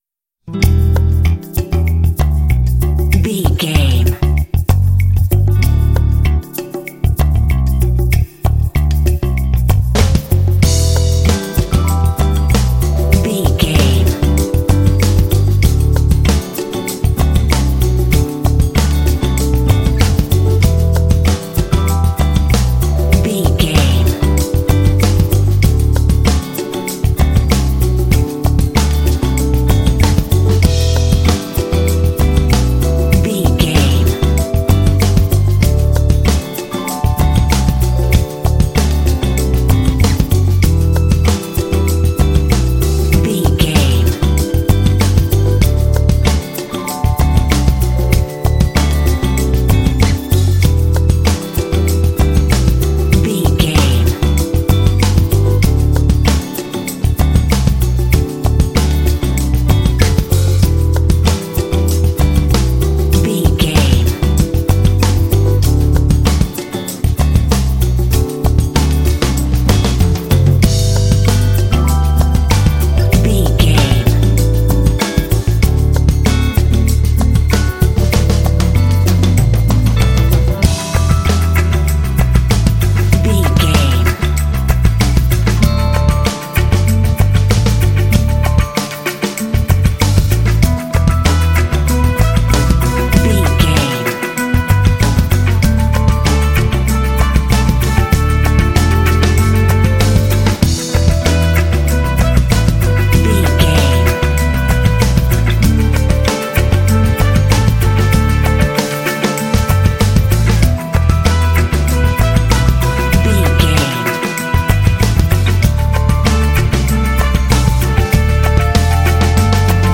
Aeolian/Minor
funky
bass guitar
acoustic guitar
drums
electric piano
strings
brass
Funk
smooth